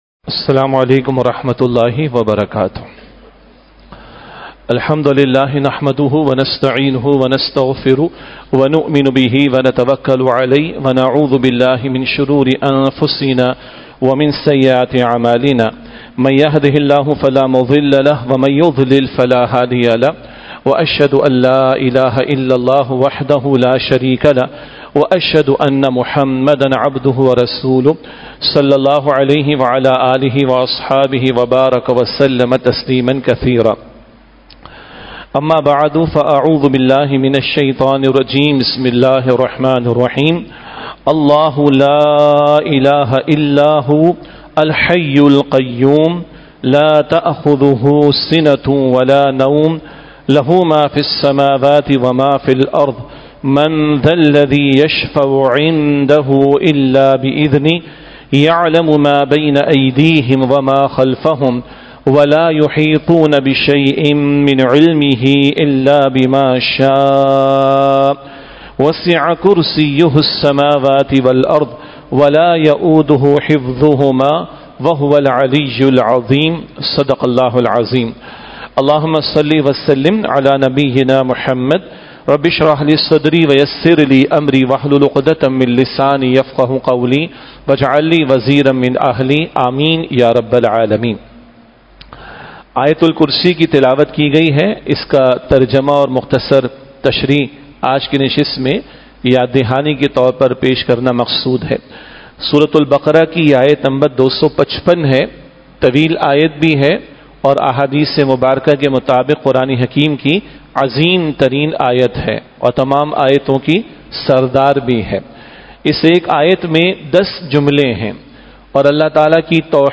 Khutbat-e-Jummah (Friday Sermons)
@ Masjid-e-Jame Al-Quran Quran Academy Defence.